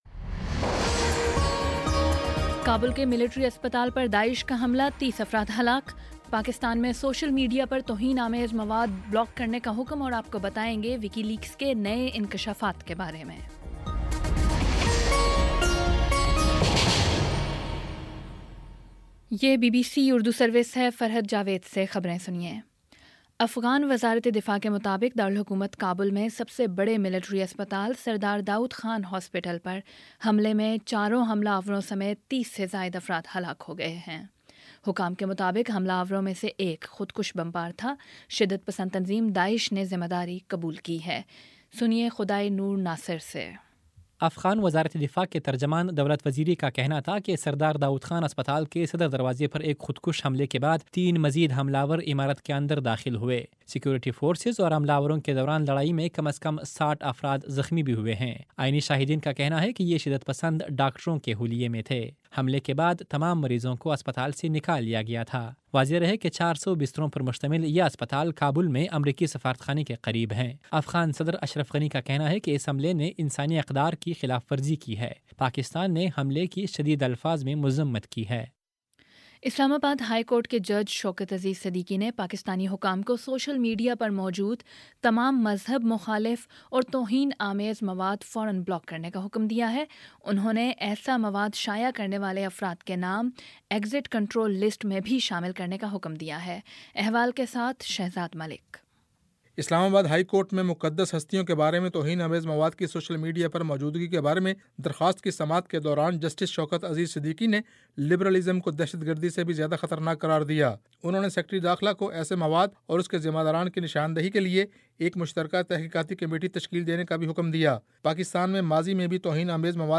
مارچ 08 : شام سات بجے کا نیوز بُلیٹن